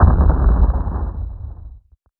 final_explosion_1.wav